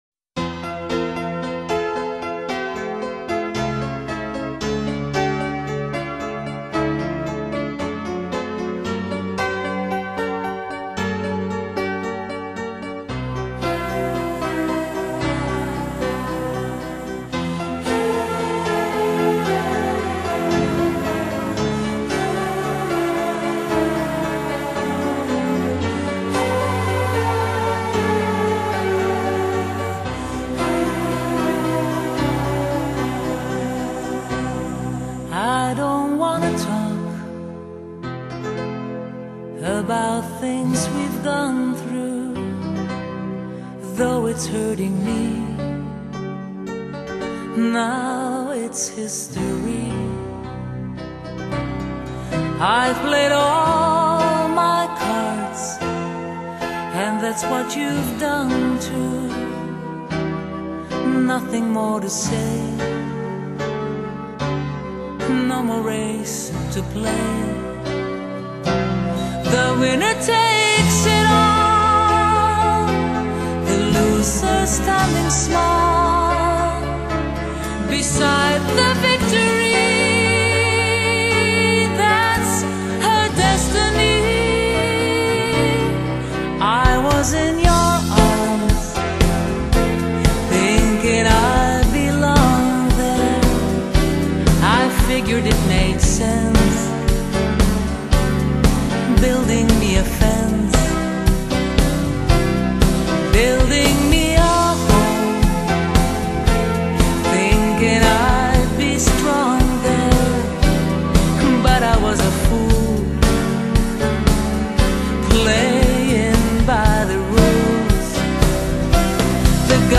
GENRE：Slow Rock